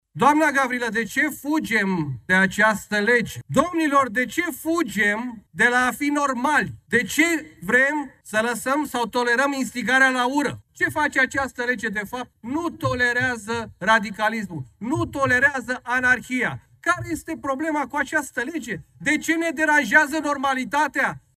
O replică a venit de la Adrian Câciu (PSD).